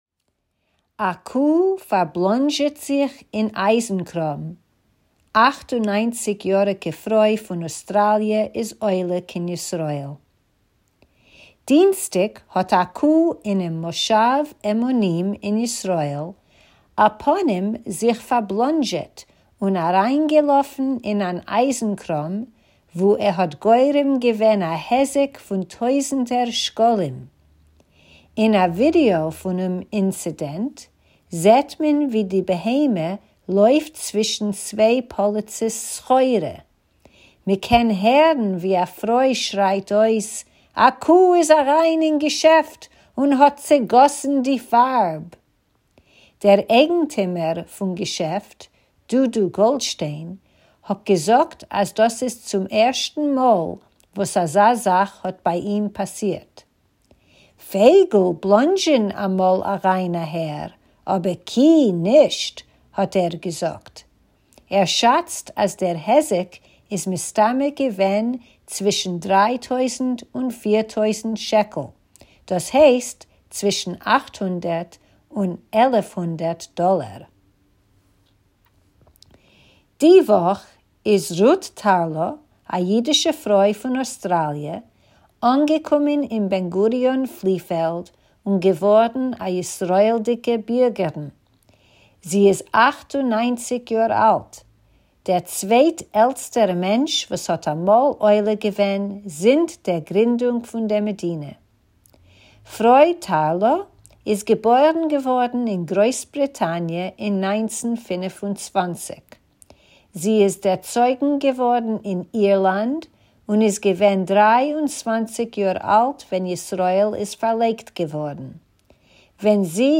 Tidbits is a weekly feature of easy news briefs in Yiddish that you can listen to or read, or both!